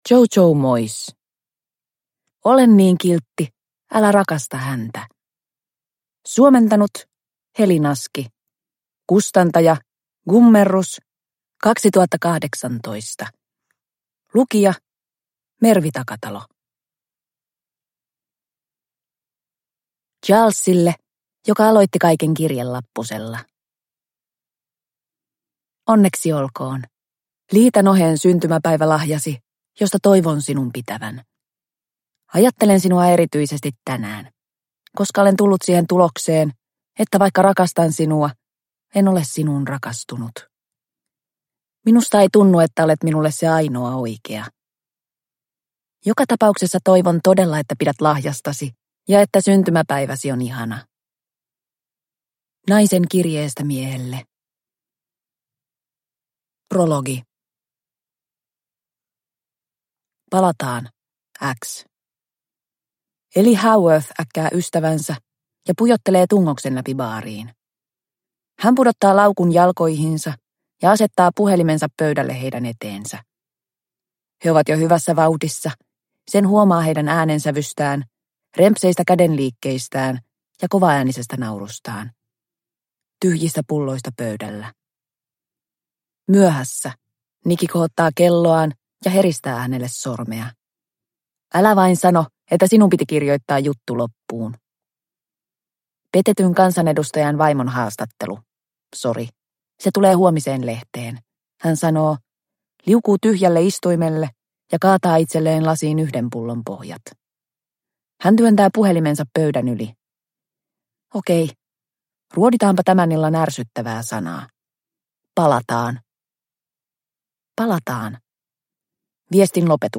Ole niin kiltti, älä rakasta häntä – Ljudbok – Laddas ner